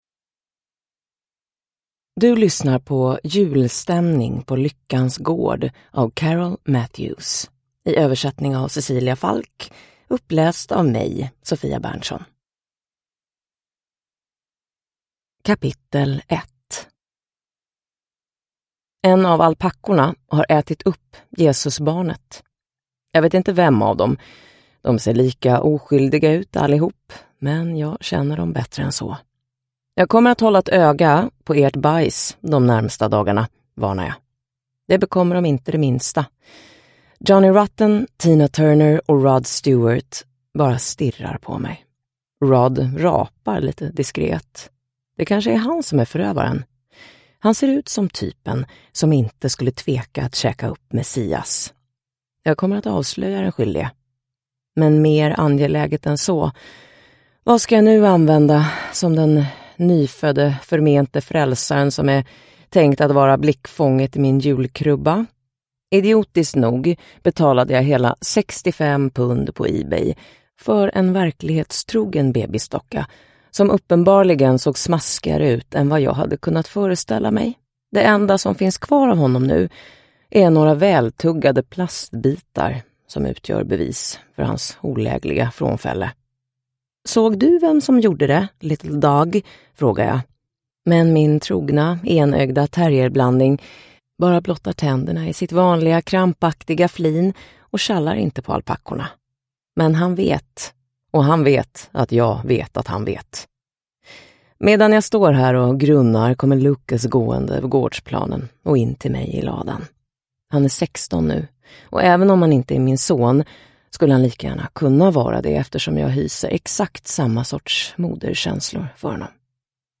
Julstämning på Lyckans Gård – Ljudbok – Laddas ner